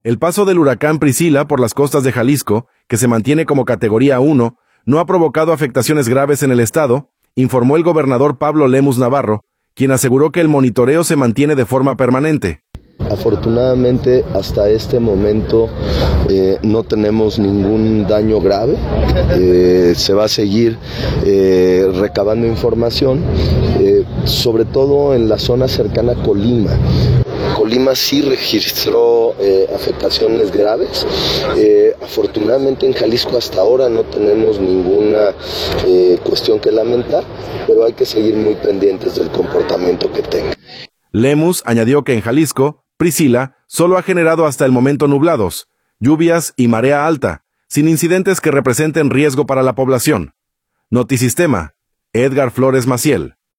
El paso del huracán “Priscila” por las costas de Jalisco, que se mantiene como categoría 1, no ha provocado afectaciones graves en el estado, informó el gobernador Pablo Lemus Navarro, quien aseguró que el monitoreo se mantiene de forma permanente.